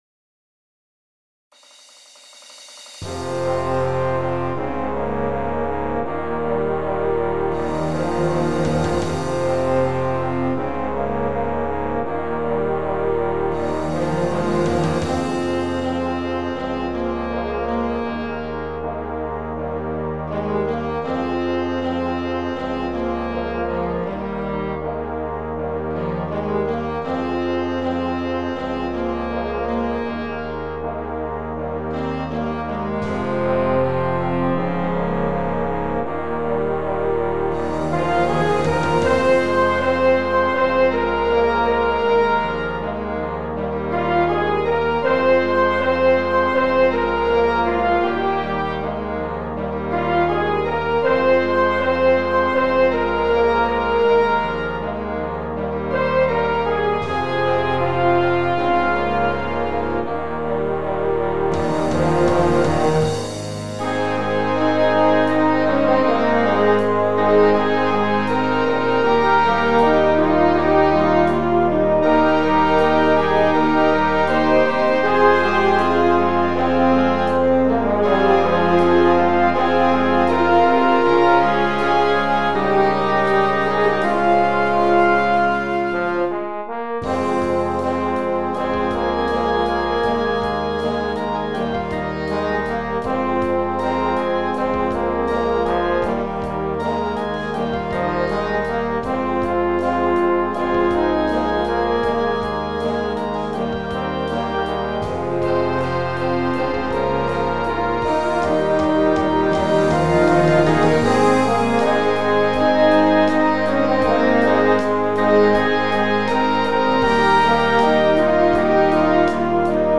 Gattung: Ballade für Blasorchester
Besetzung: Blasorchester